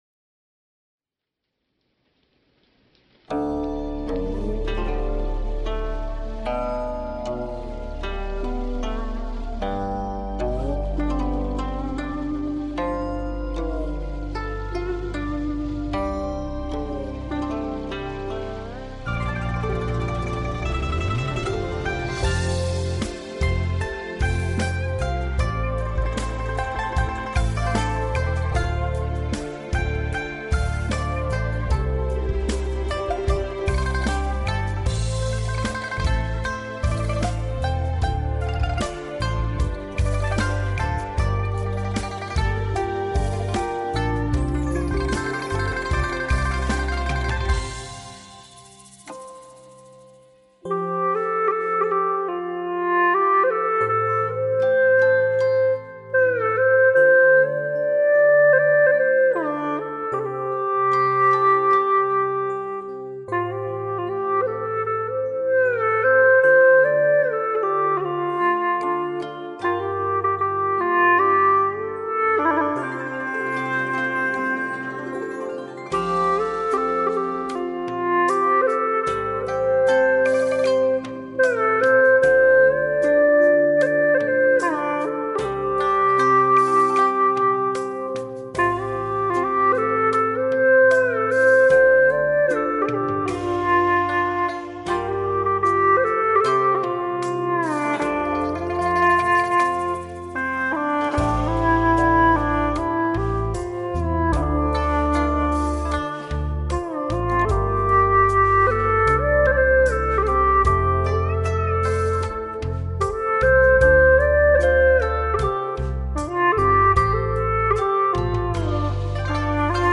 调式 : F 曲类 : 古风